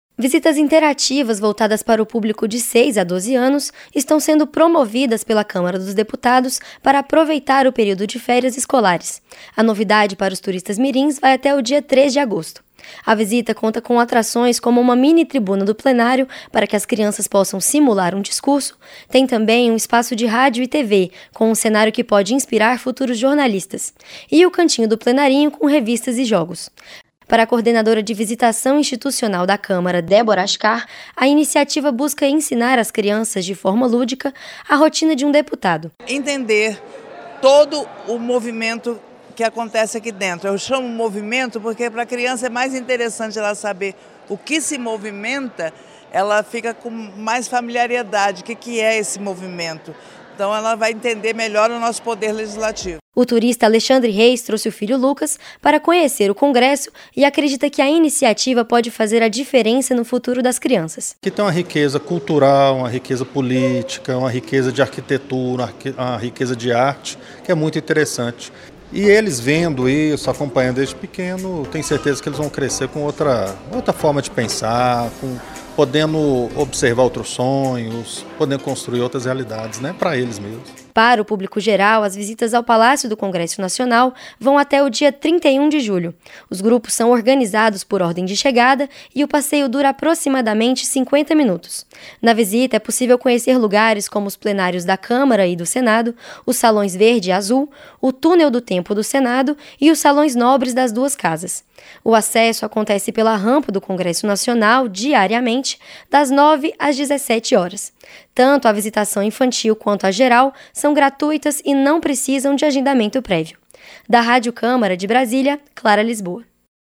Câmara oferece visita voltada ao público infantil nas férias - Radioagência